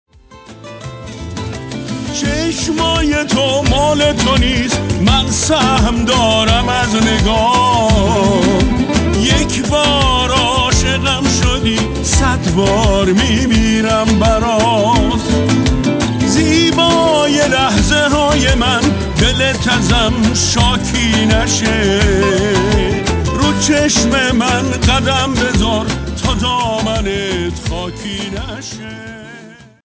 زنگ موبایل شاد